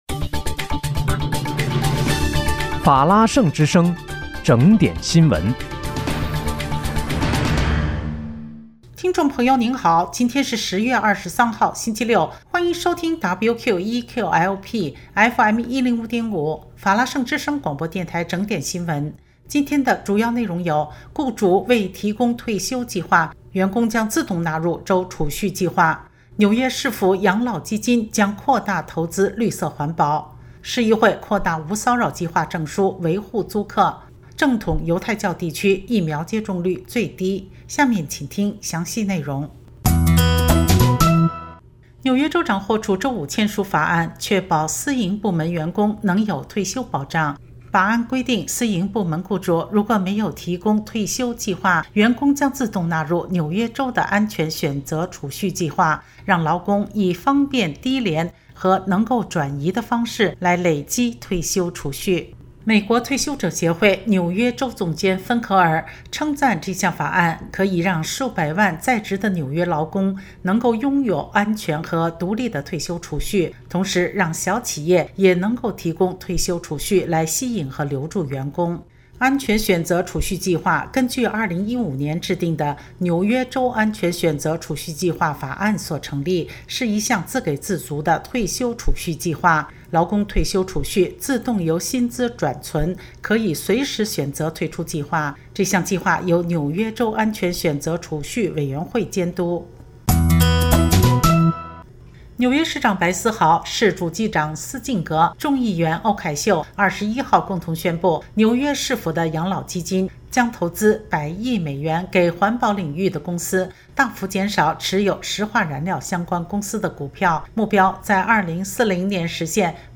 10月23日（星期六）纽约整点新闻